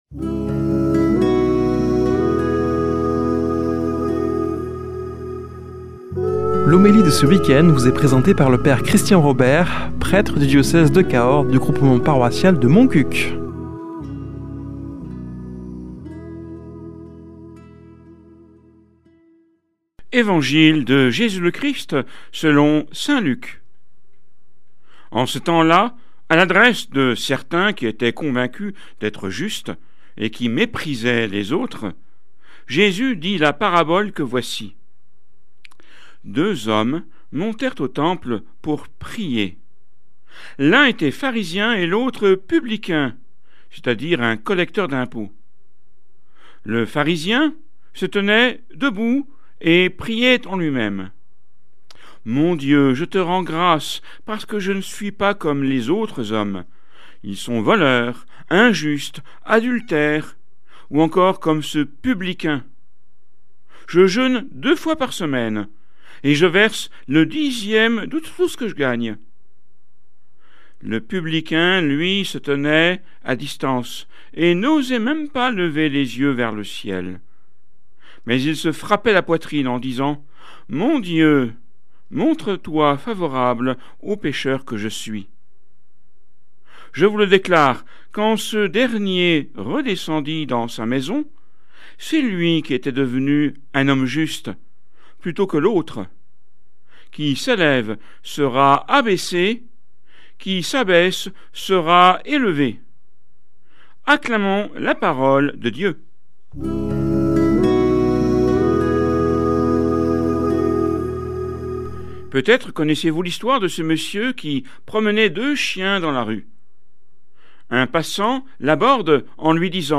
Homélie du 25 oct.